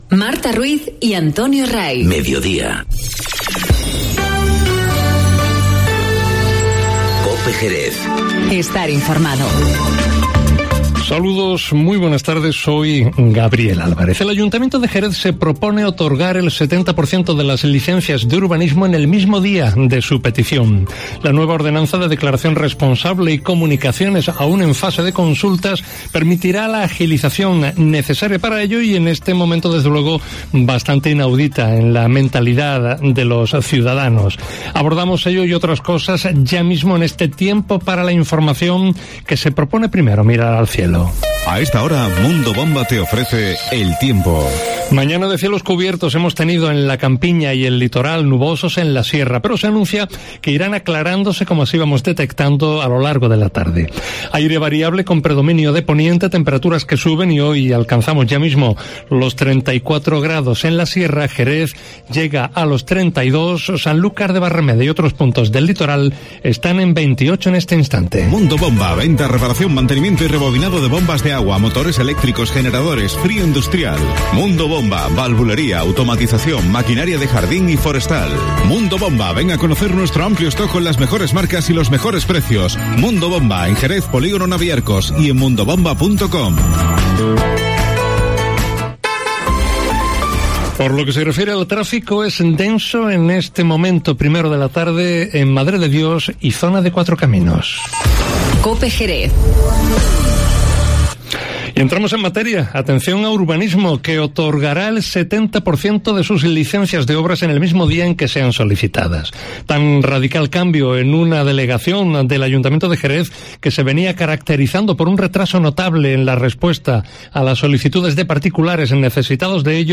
Informativo Mediodía COPE en Jerez 10-07-19